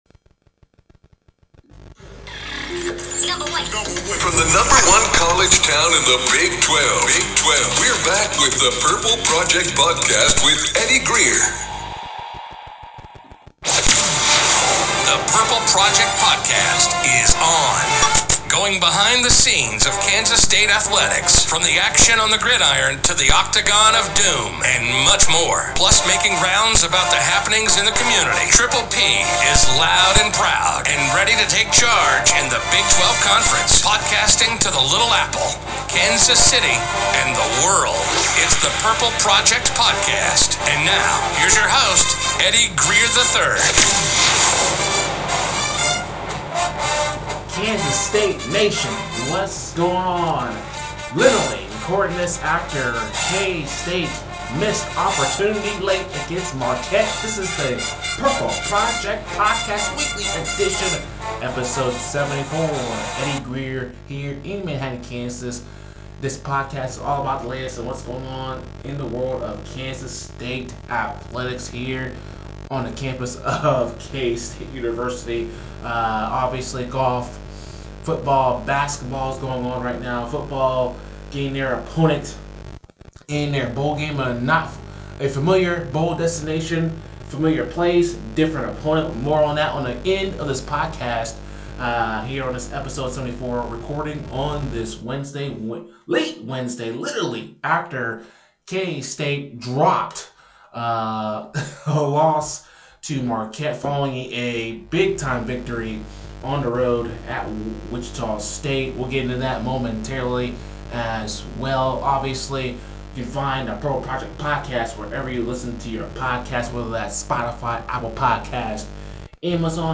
Episode 74 of the Purple Project Podcast, the fellas discuss the loss against Marquette at home and what that means. Also, women's basketball & K-State bowl matchup against LSU.